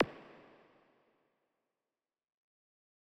KIN Zap 1.wav